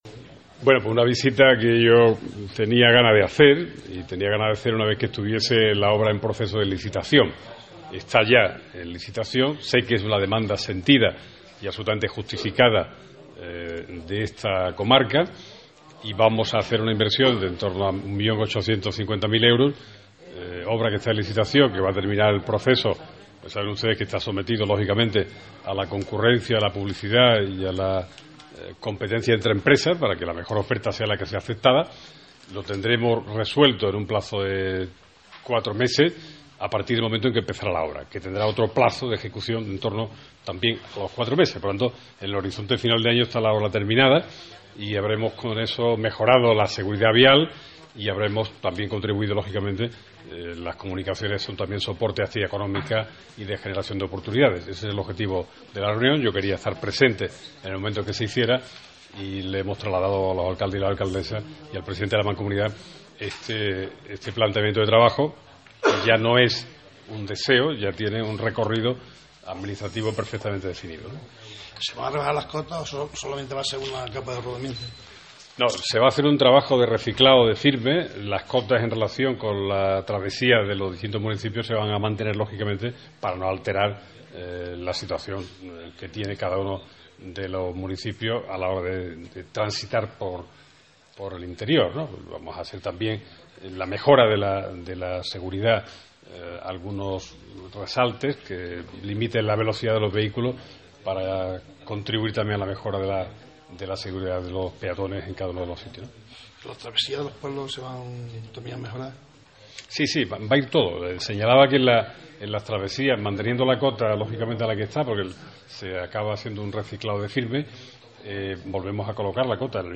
El Consejero de Fomento y Vivienda de la Junta de Andalucía informa del arreglo de la carretera A-422, Alcaracejos – Hinojosa del Duque, con una inversión de 1,8 millones de euros, a los alcaldes de Los Pedroches.
[sonido recogido por Ser Los Pedroches]